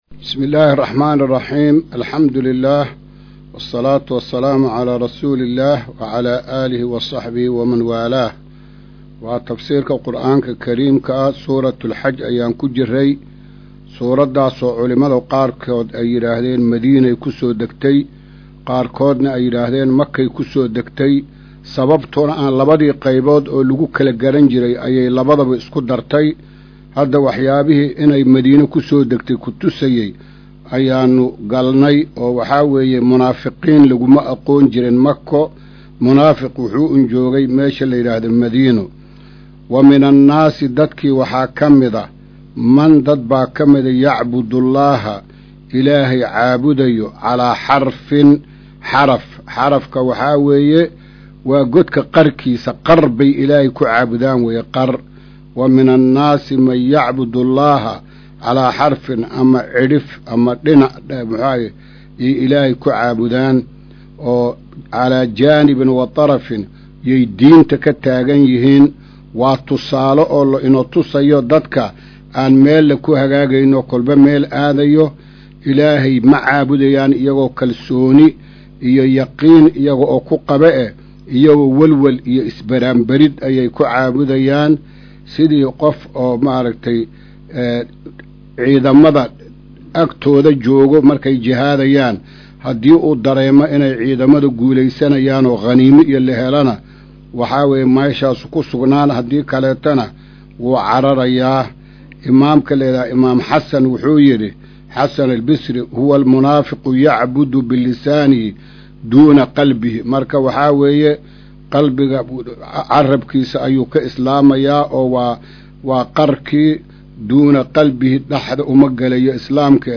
Maqal:- Casharka Tafsiirka Qur’aanka Idaacadda Himilo “Darsiga 161aad”